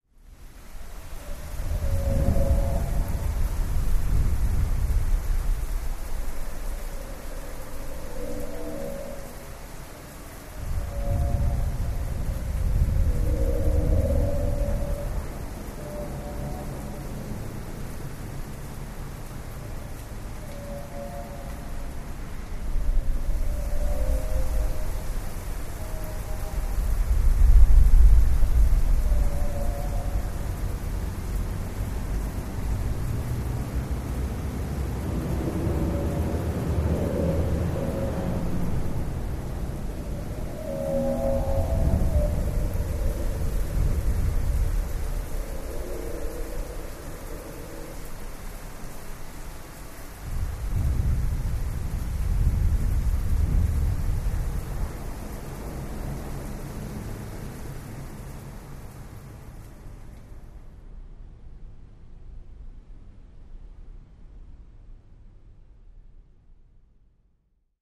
Methane Rain steady rain effects with distant heavy hits and high pitch moans